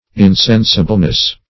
Insensibleness \In*sen"si*ble*ness\, n.